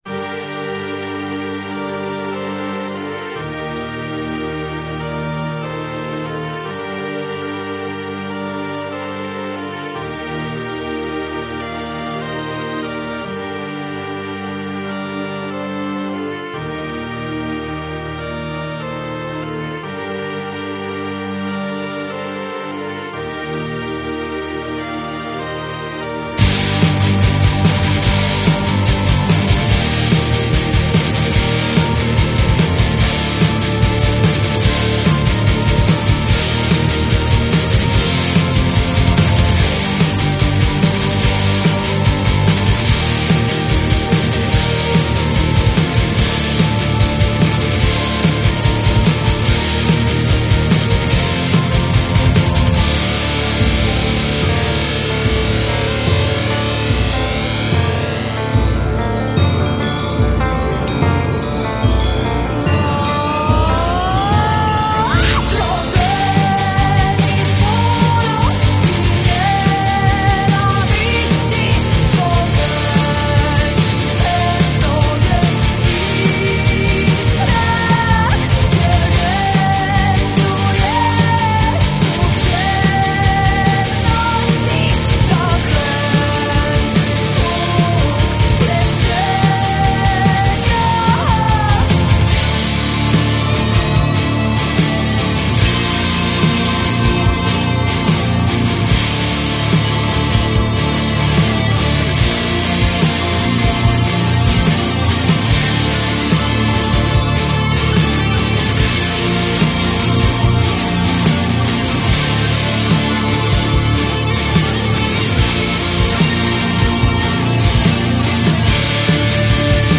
(gotický rock/metal)